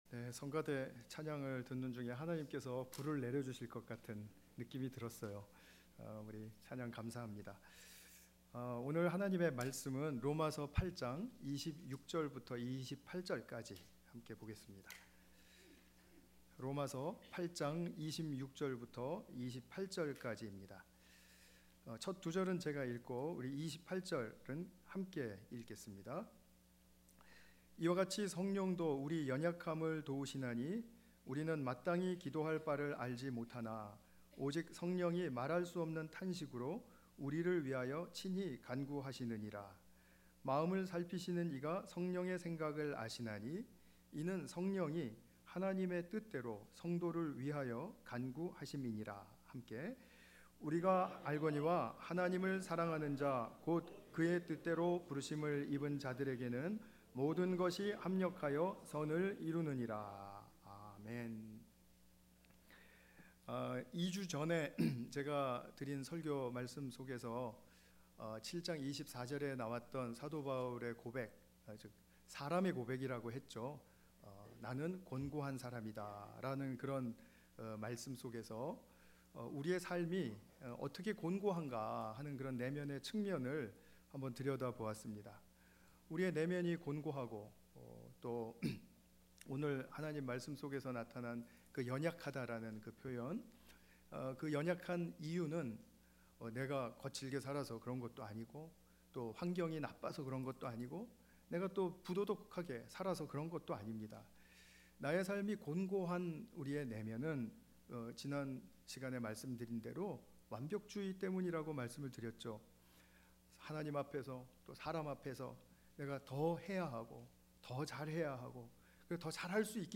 복음의 여정 (6) – 성령을 따르는 삶 – Korean Baptist Church of Gainesville